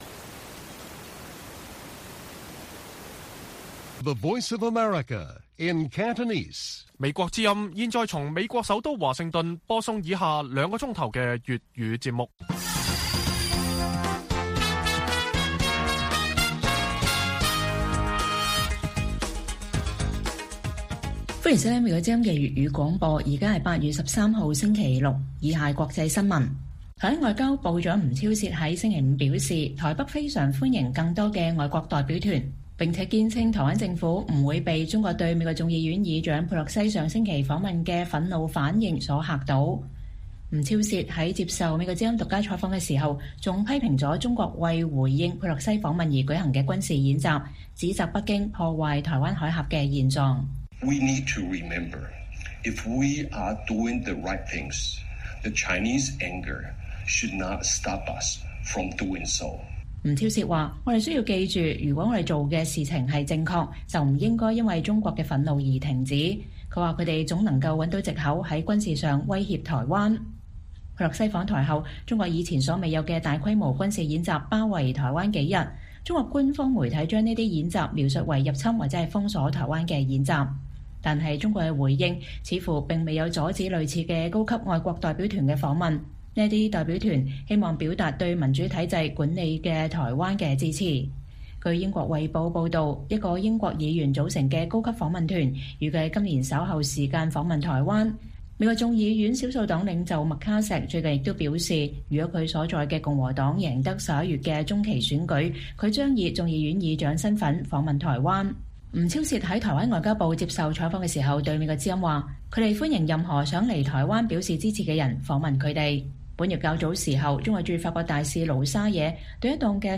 粵語新聞 晚上9-10點：美國之音獨家專訪台灣外長吳釗燮：歡迎更多外國代表團來台